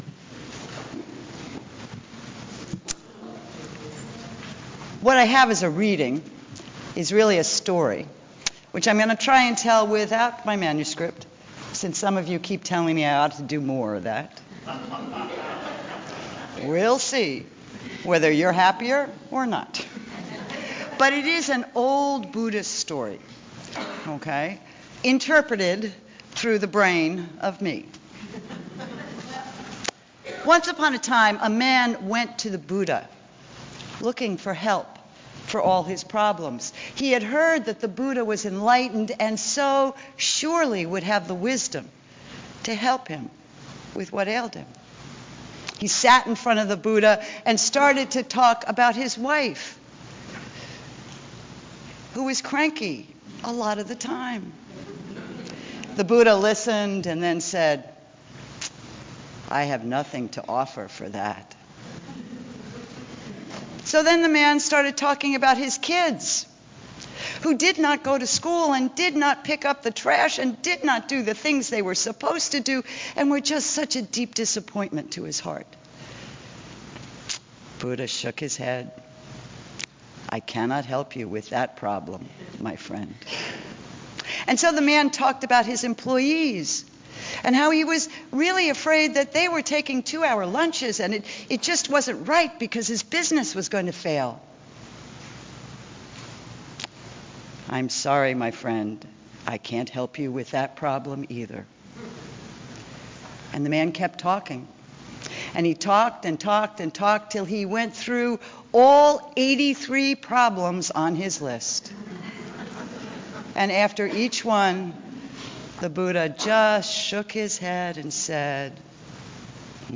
by | Oct 6, 2013 | Recorded Sermons | 0 comments